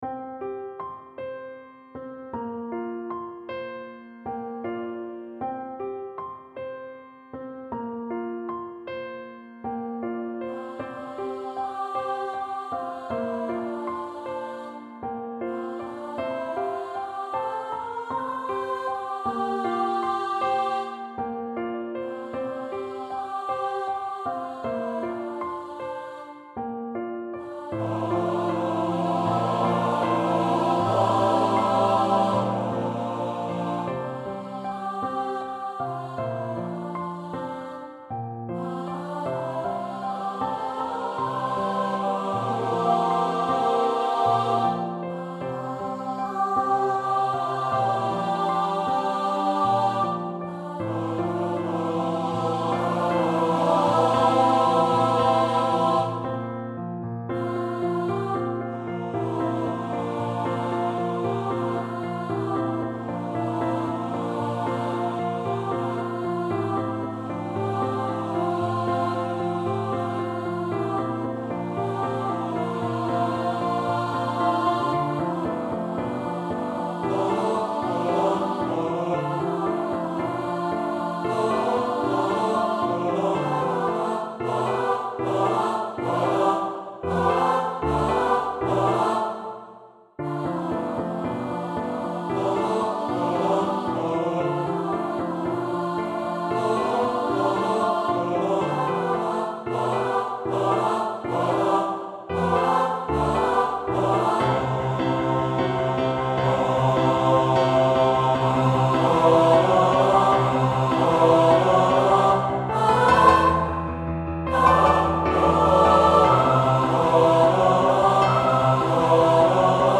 A dynamic song, an exciting concert piece.
SATB with piano